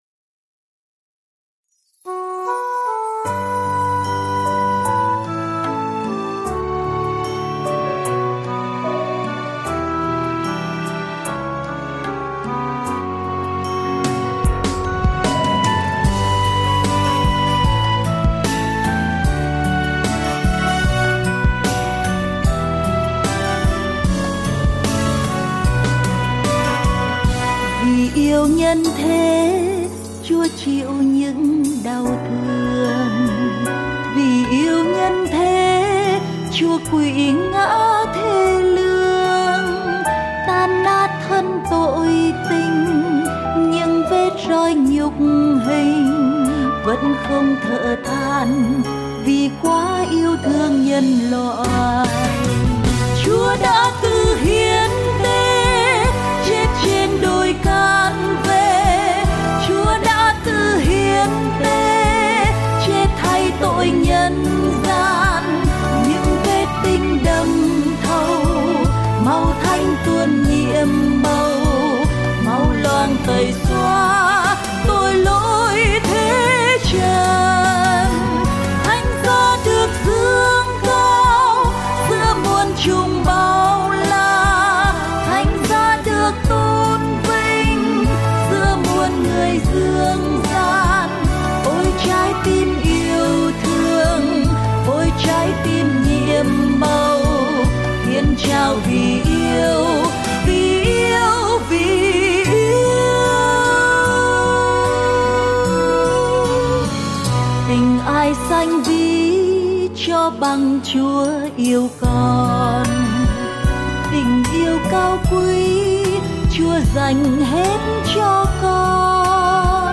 thuộc chuyên mục nhạc mùa chay